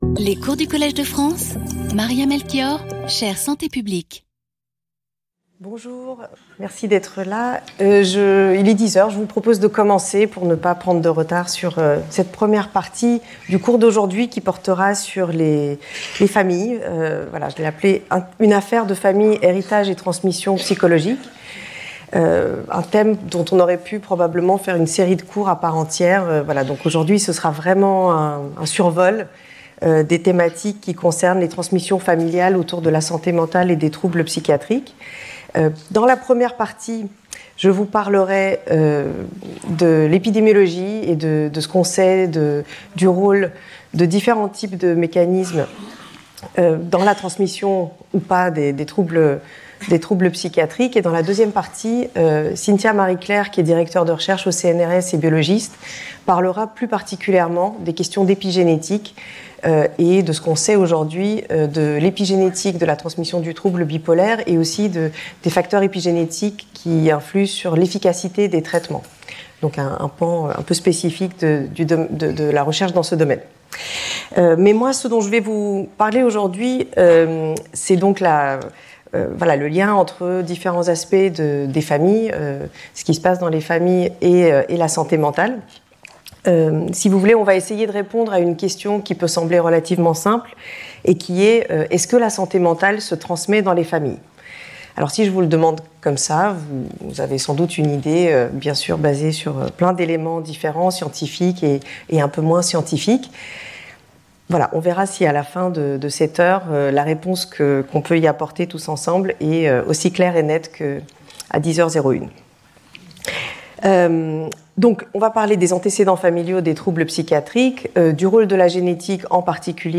The lecture will be followed by a seminar on epigenetic transmission mechanisms that could contribute to the risk of intergenerational transmission of psychological difficulties and addiction.